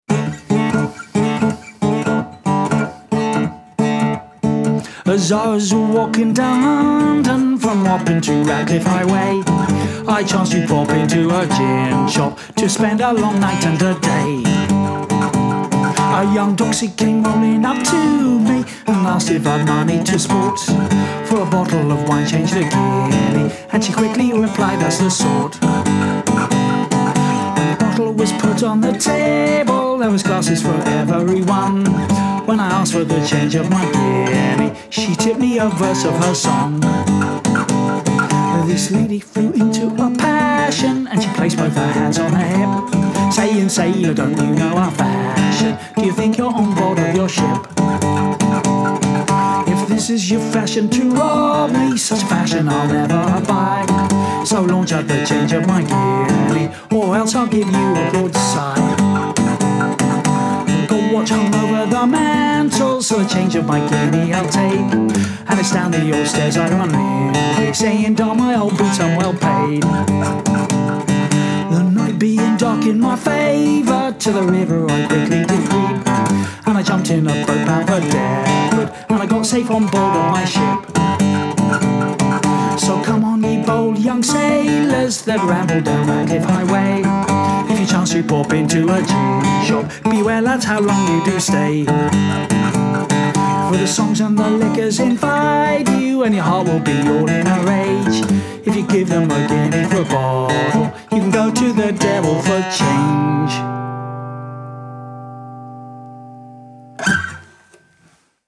traditional British songs